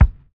DISCO 2 BD.wav